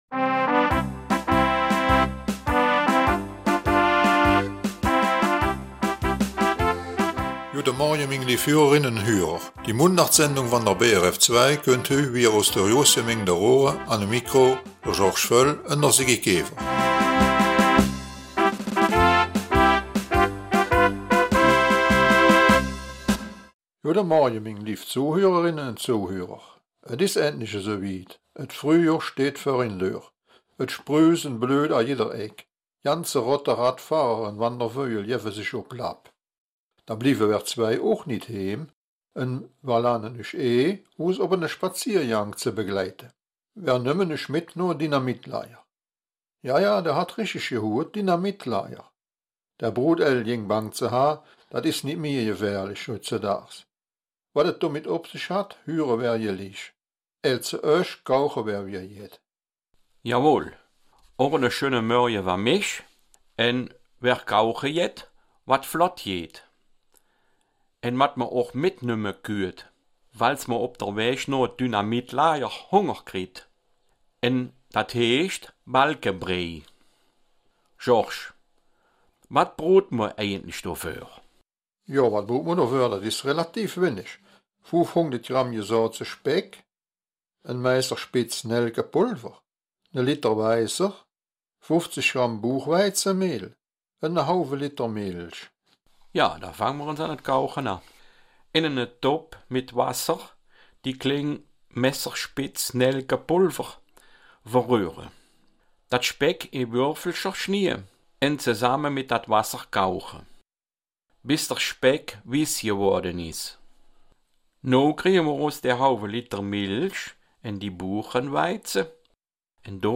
Raerener Mundart - 13. April